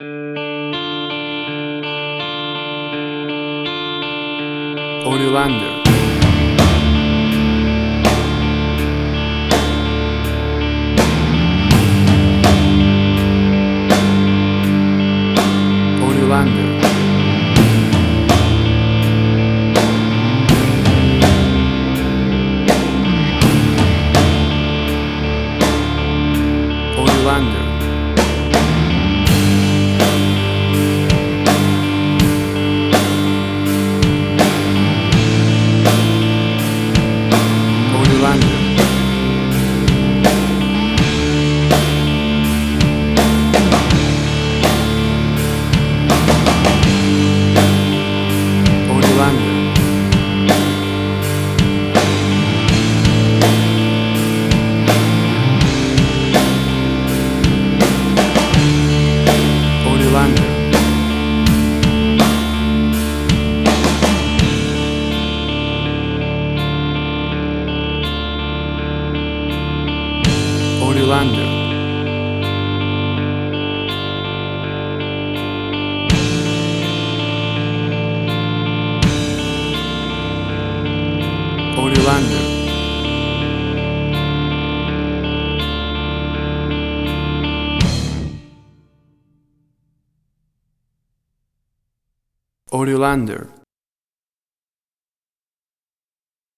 Tempo (BPM): 82